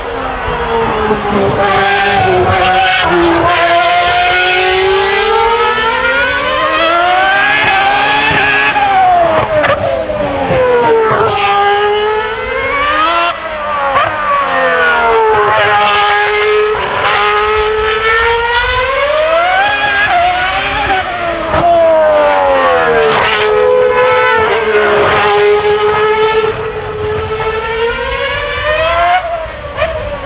Hör hur "körbar" en modern V10 motor är jämfört med en äldre V12 motor.
Visst saknar man V12 ljudet, men hör hur mycket svårare den är i kurvor och vid gaspådrag.